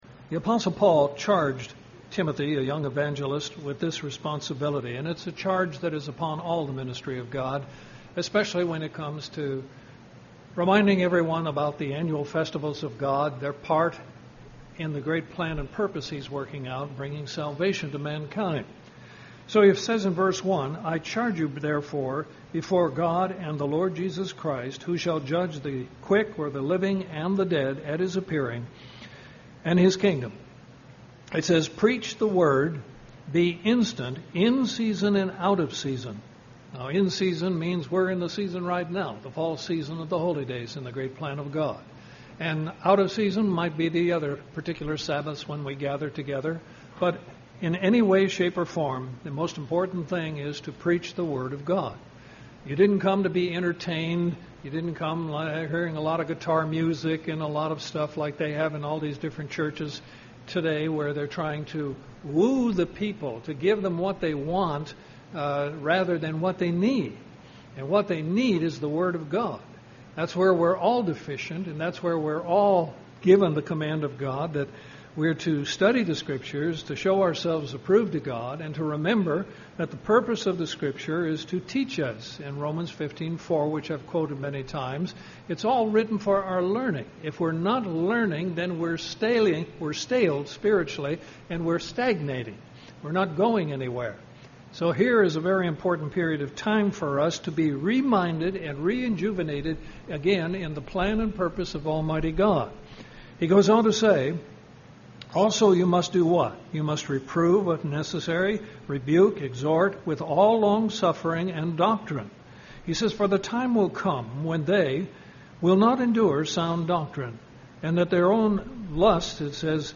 Given in Columbus, GA
UCG Sermon Studying the bible?